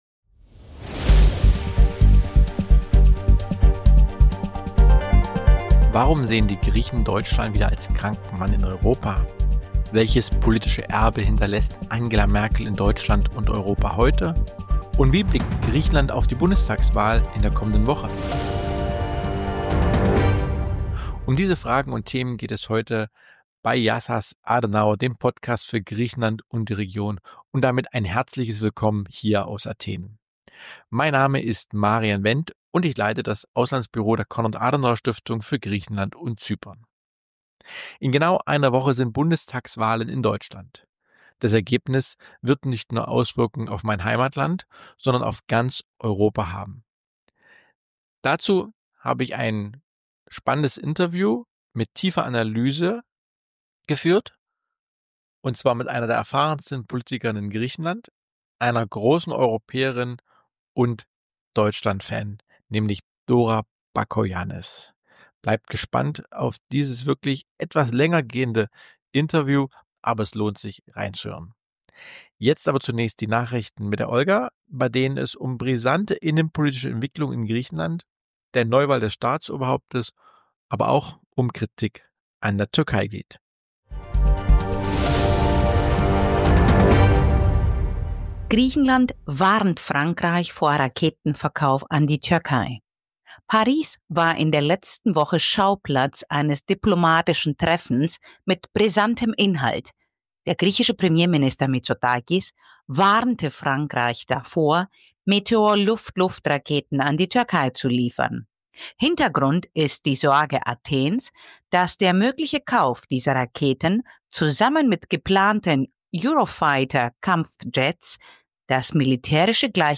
In dieser spannenden Episode spricht Host Marian Wendt mit Dora Bakoyannis, einer der herausragenden politischen Persönlichkeiten Griechenlands.